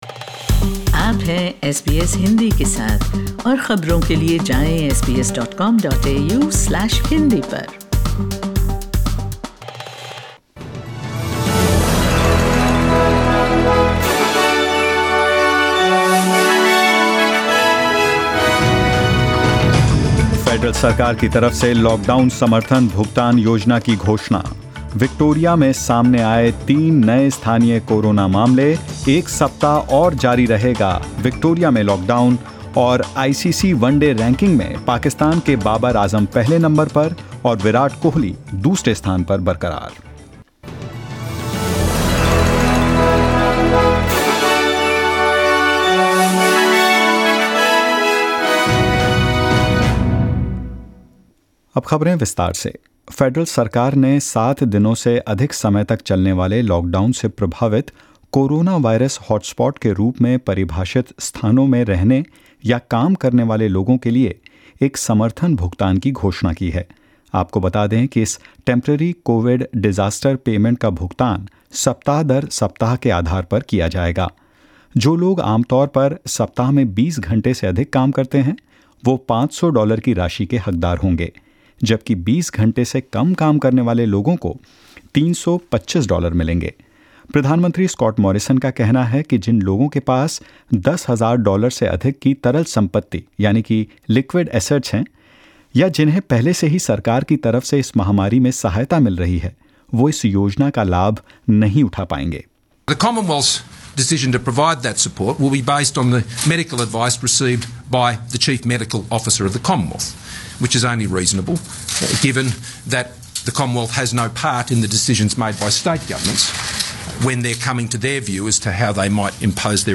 In this latest SBS Hindi News bulletin of Australia and India: Victoria records three new local COVID-19 cases; Indian cricket captain Virat Kohli and vice-captain Rohit Sharma maintain second and third positions in the ICC ODI rankings and more.